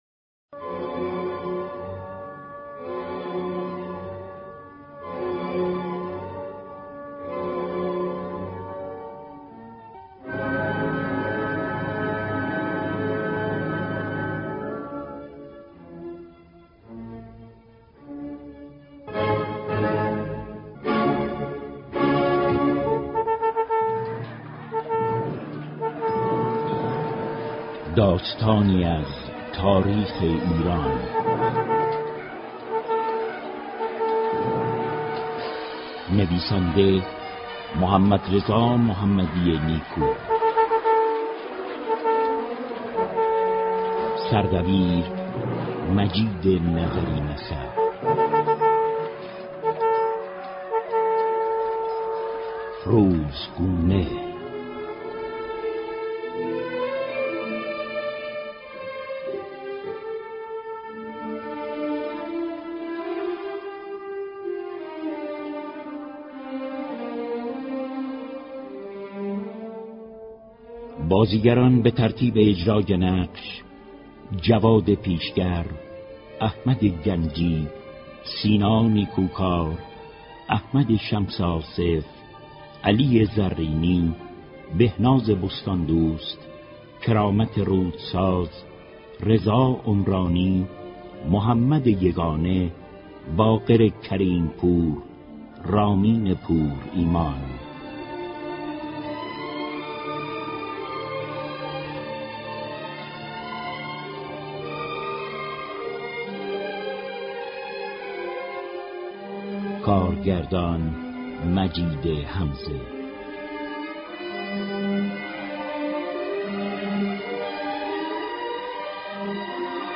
نمایش رادیویی روزگونه را در ده قسمت با هم خواهیم شنید.